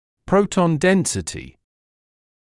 [‘prəutɔn ‘densɪtɪ][‘проутон ‘дэнсити]плотность протонов